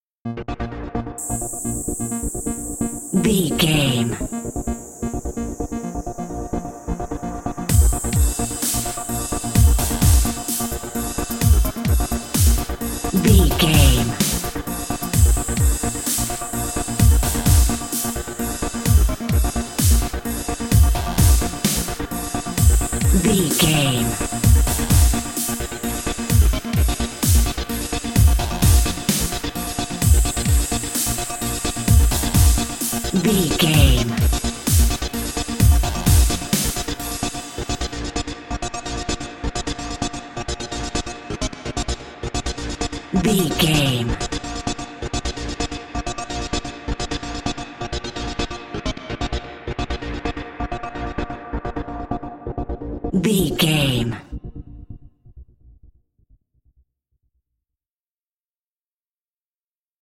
Epic / Action
Fast paced
Aeolian/Minor
B♭
Fast
aggressive
dark
driving
energetic
drum machine
synthesiser
breakbeat
synth leads
synth bass